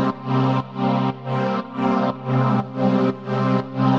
Index of /musicradar/sidechained-samples/120bpm
GnS_Pad-alesis1:4_120-C.wav